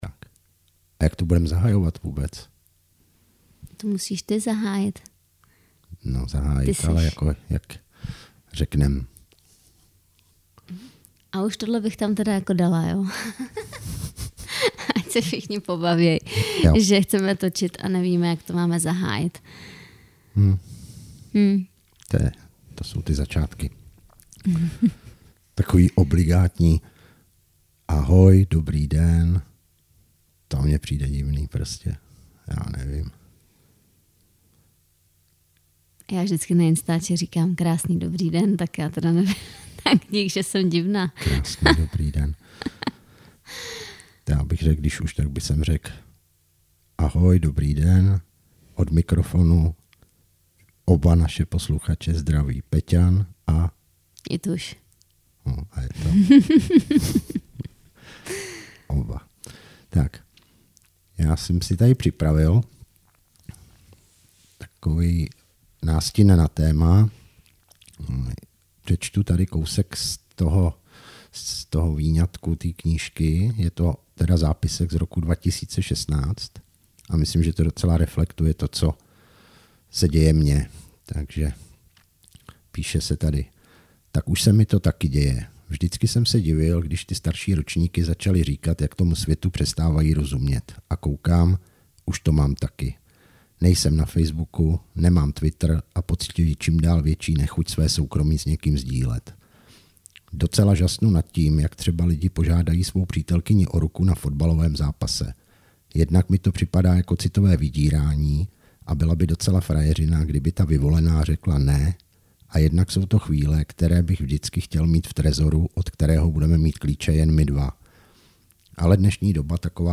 Napadlo nás, že bysme si mohli zkusit natočit naše rozpravy, které doma vedeme, ať to máme taky někde zaznamenané a můžeme si to třeba i za nějakou dobu připomenout.